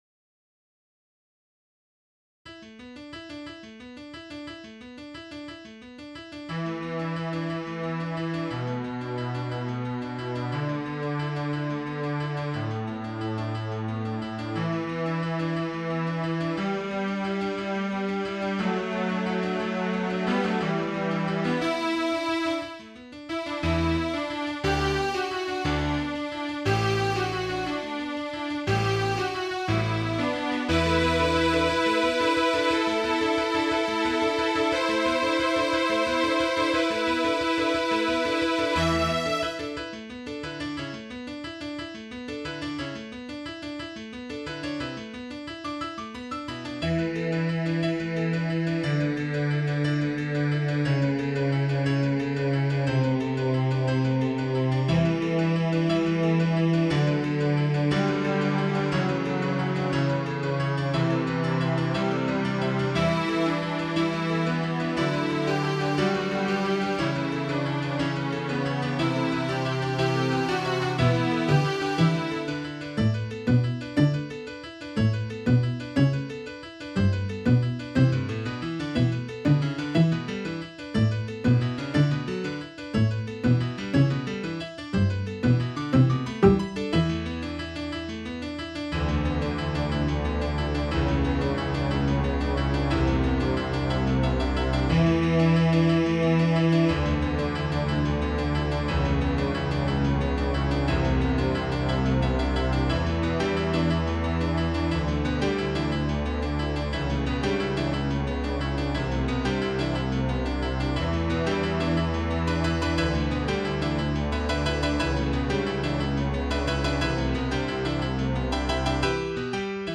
An odd time signature on this one.
For conducting, I’d start in two and later go to four. mp3 download wav download Files: mp3 wav Tags: Trio, Piano, Strings Plays: 2049 Likes: 0